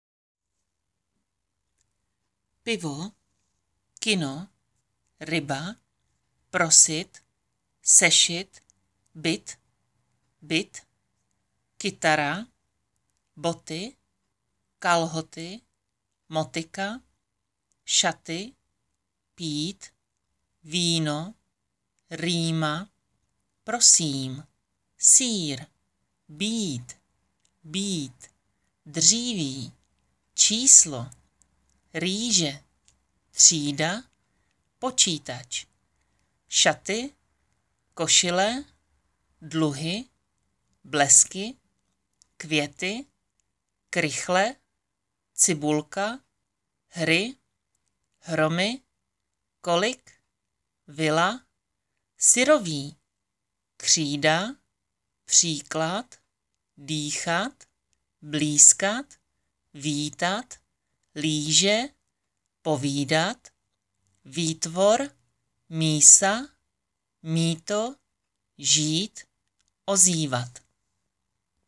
Děkuji za zájem o nahrávku výslovnost I a Y - slova
Tady si můžete stáhnout audio na výslovnost I a Y – slova